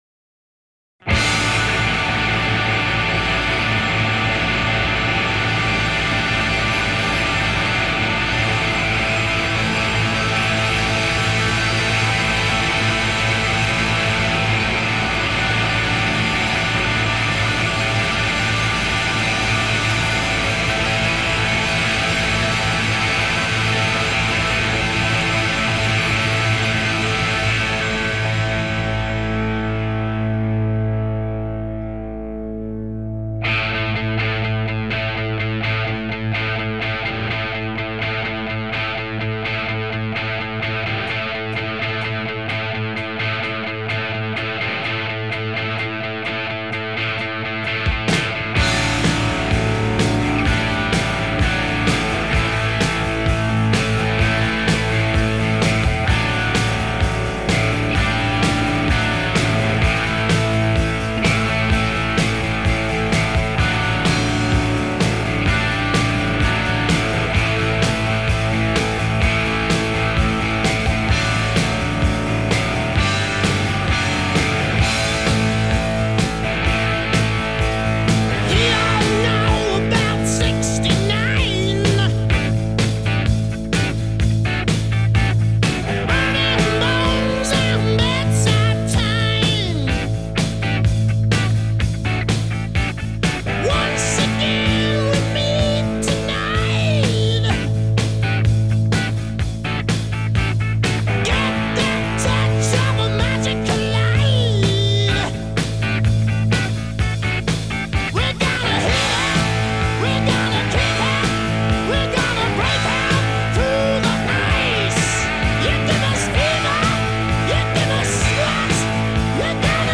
Жанры: Хэви-метал, Хард-рок